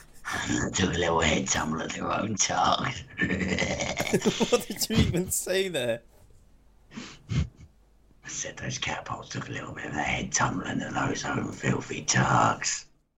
Catapult Head Tumble